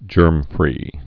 (jûrmfrē)